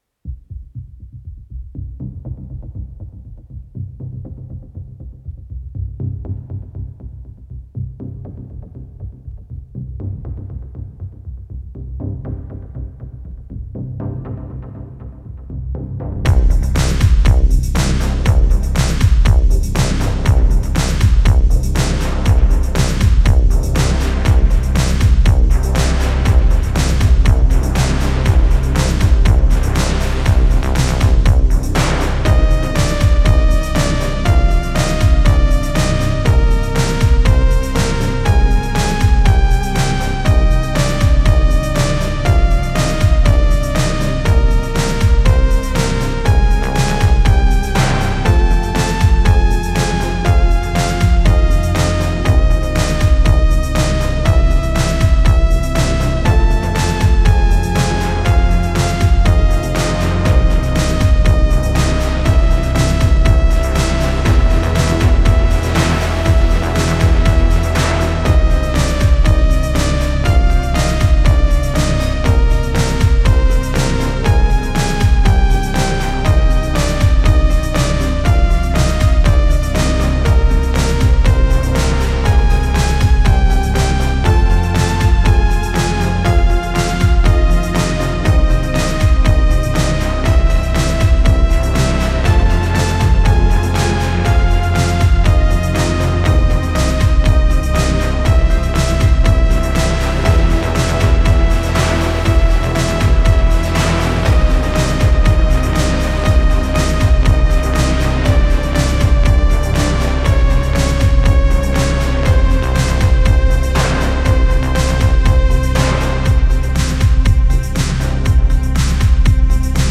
#24 'Timevault' (a curious blend of synthwave and medieval music!?!)